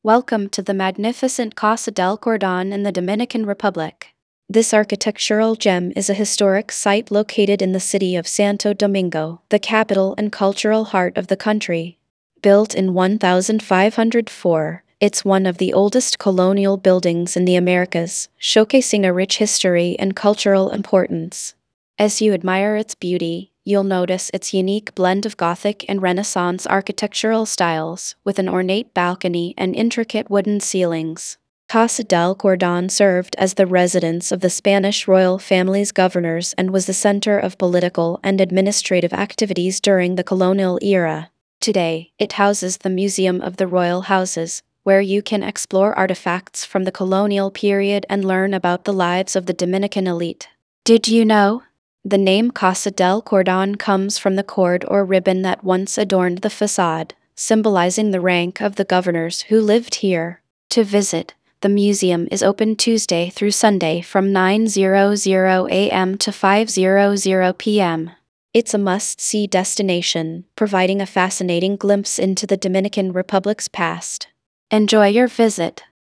karibeo_api / tts / cache / 77a34b6fbc44b2ade8017cf519bb3f39.wav